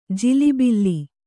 ♪ jilibilli